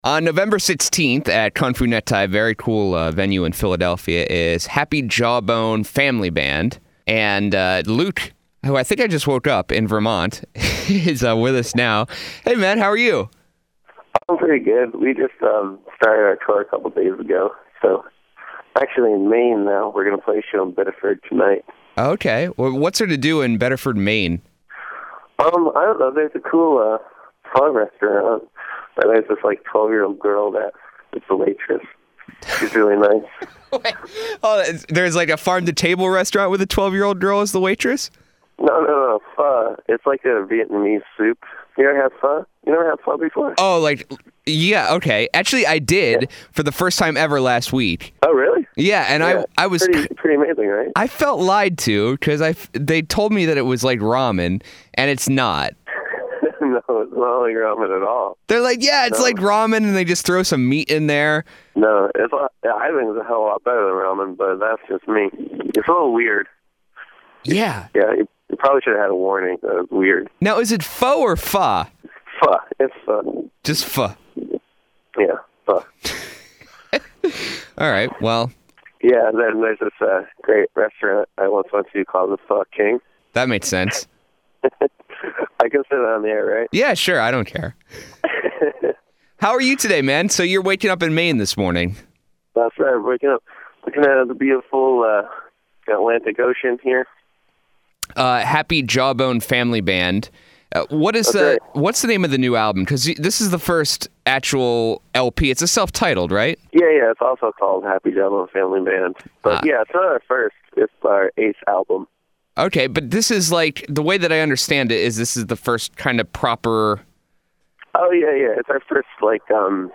Interview: Happy Jawbone Family Band
happy-jawbone-family-band-interview.mp3